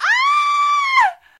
scream2.wav